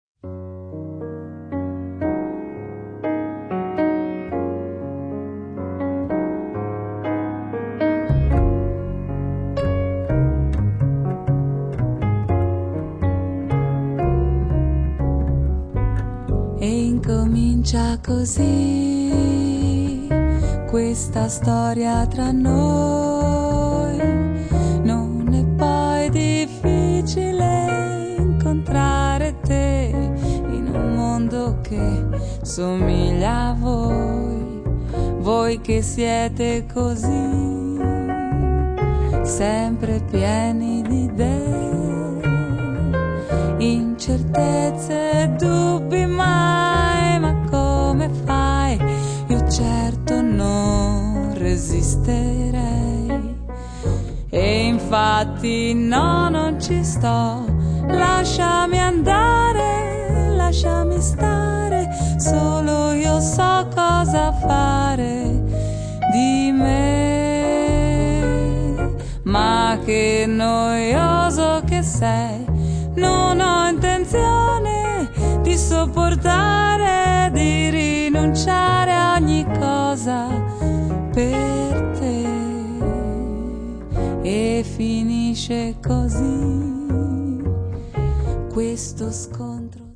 La bella e morbida voce